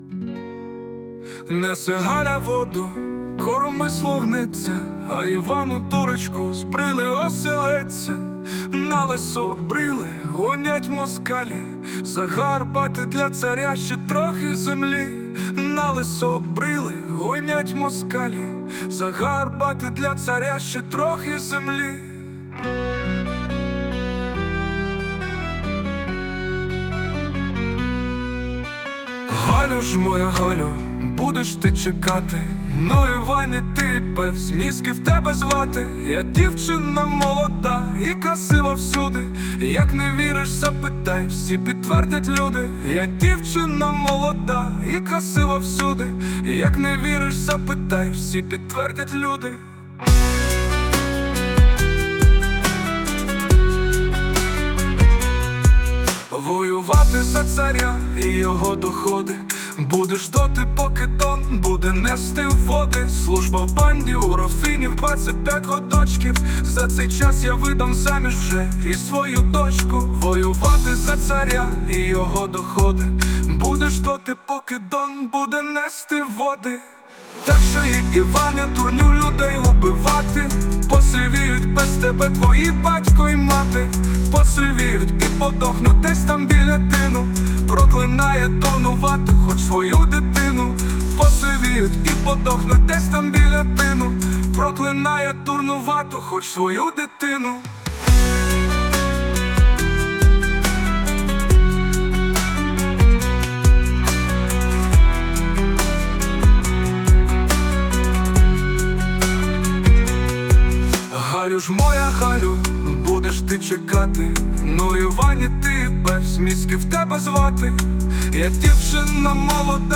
ТИП: Пісня
СТИЛЬОВІ ЖАНРИ: Ліричний